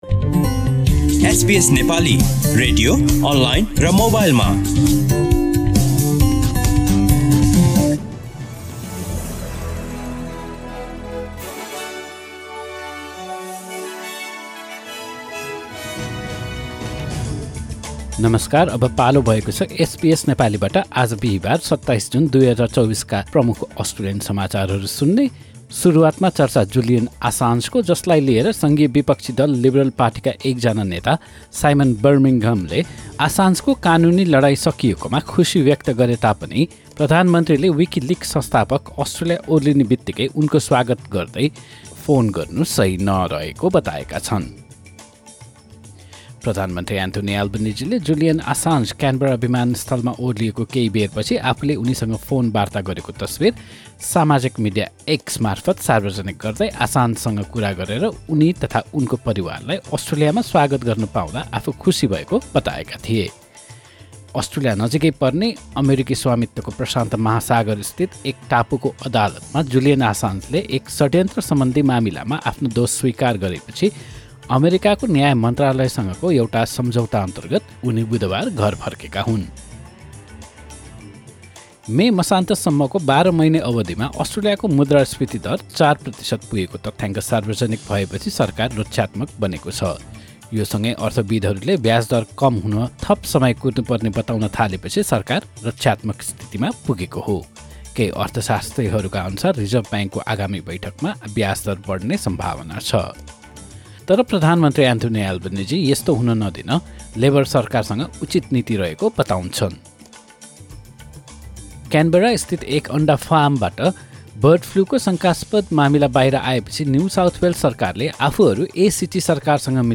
SBS Nepali Australian News Headlines: Thursday, 27 June 2024